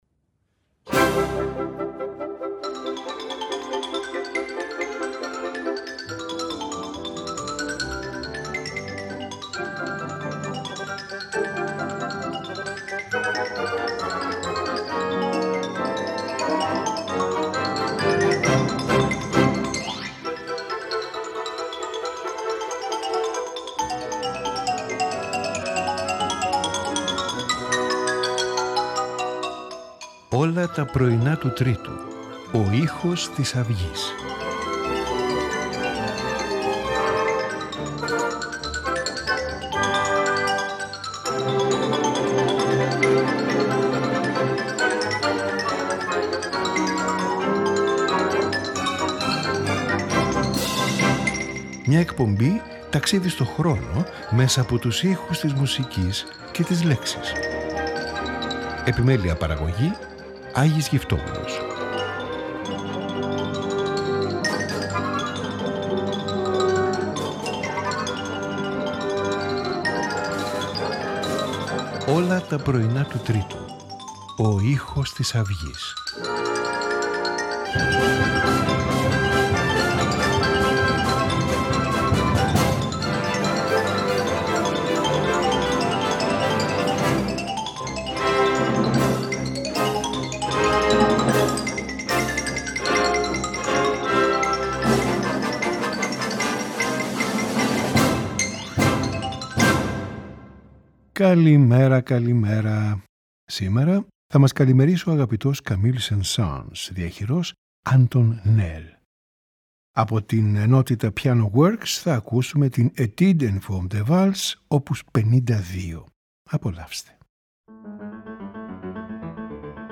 Trio for Flute, Cello and Piano
Sonata for Violin and Piano in A major
Piano Sonata No. 13 in A major
String Quartet in B-flat major
Sonata for Cello and Basso Continuo